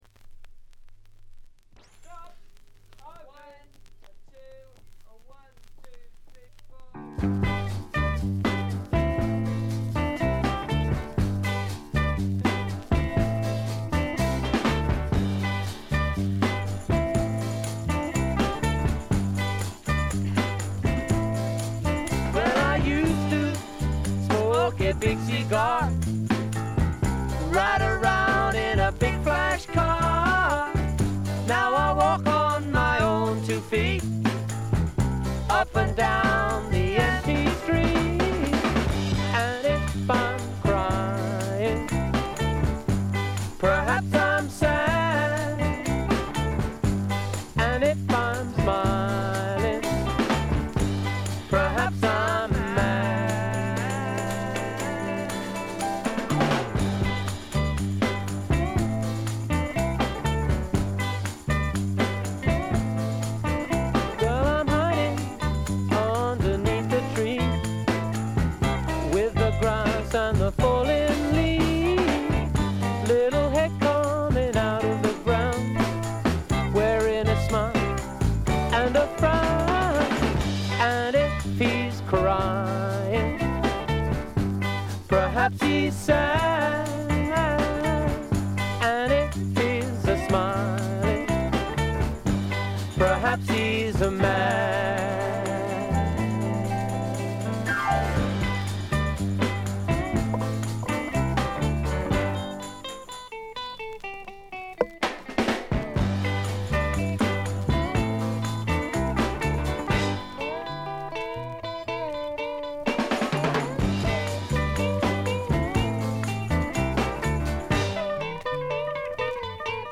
静音部で軽微なチリプチやバックグラウンドノイズ。
非トラッド系英国フォーク至宝中の至宝。
試聴曲は現品からの取り込み音源です。